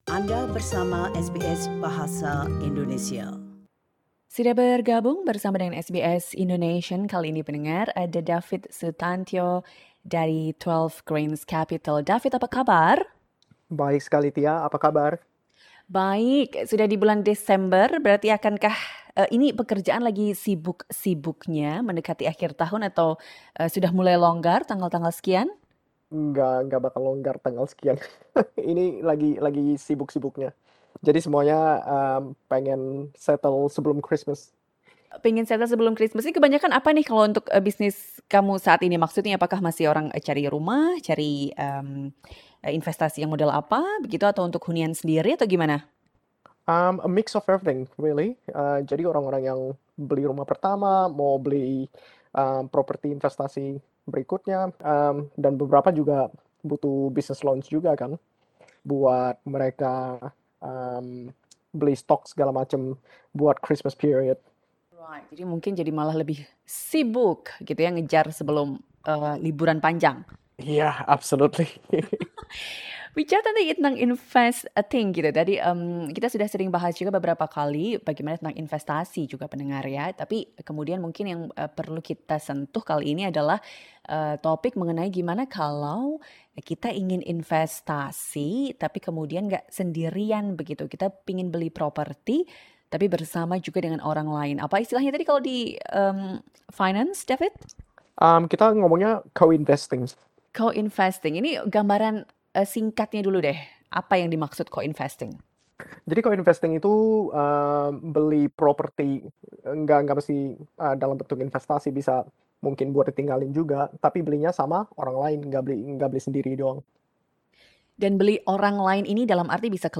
Here is snippet of the interview.